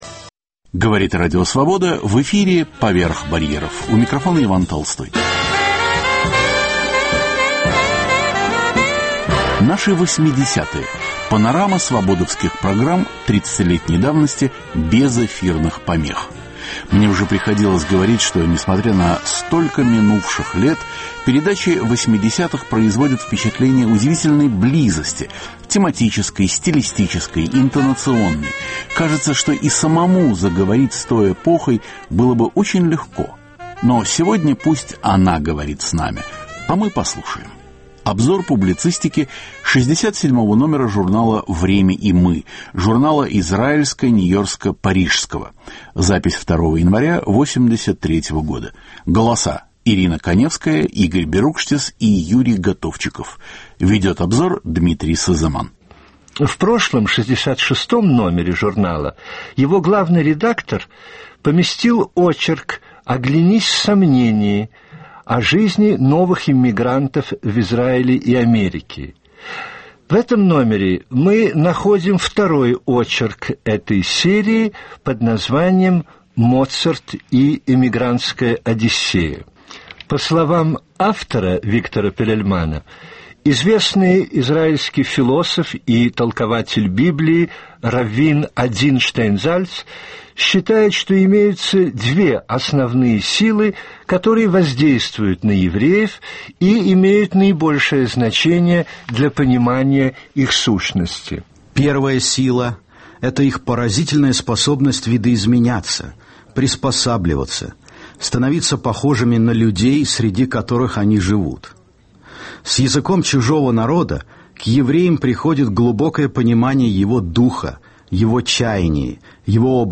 Наши 80-е. Панорама передач 30-летней давности, впервые без глушения. В передаче - обзор публицистики журнала "Время и мы" и очередной юбилей Радио Свобода. Эфир 1983 года.